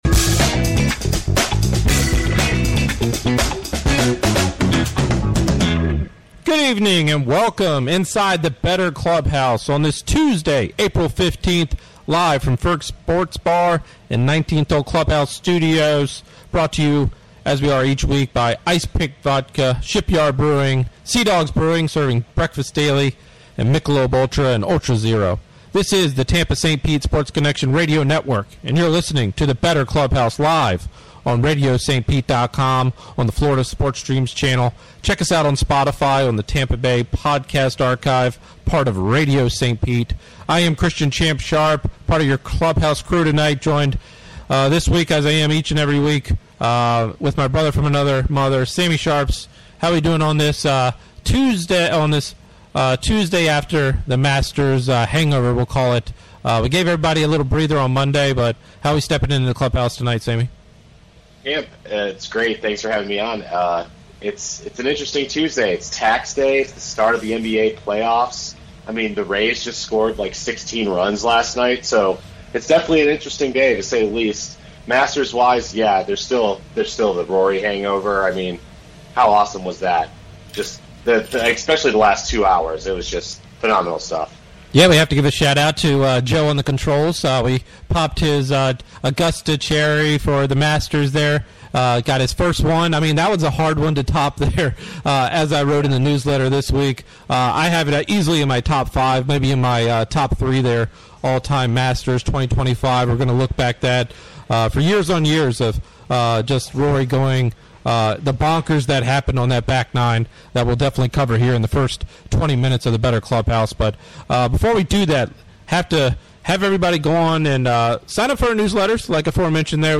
"The Bettor Clubhouse" 4-15-25; Live from Ferg's Tuesdays 7pm ET